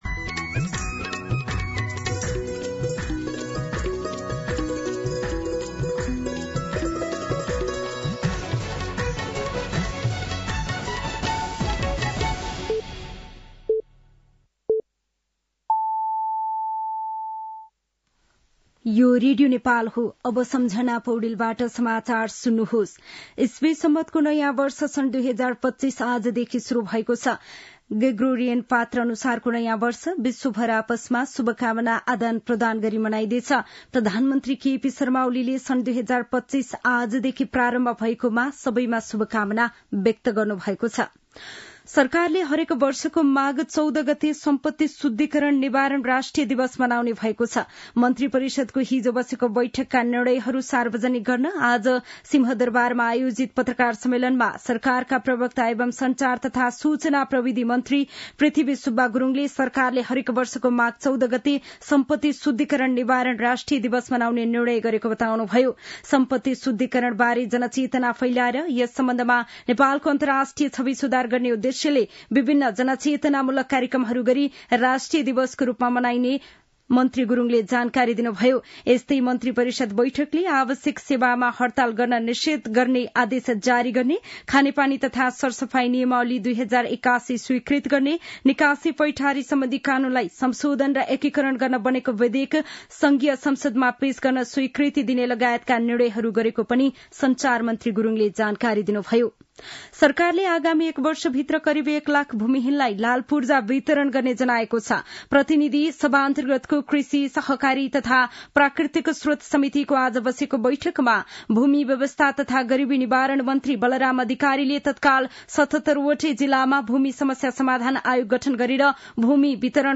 साँझ ५ बजेको नेपाली समाचार : १८ पुष , २०८१
5-PM-Nepali-News-9-17.mp3